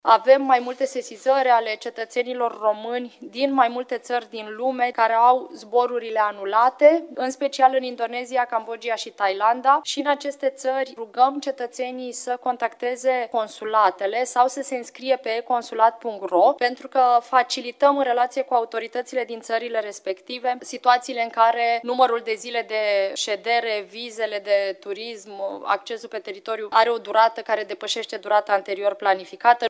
Ministra de Externe, Oana Țoiu: „Avem mai multe sesizări ale cetățenilor români din mai multe țări din lume care au zborurile anulate”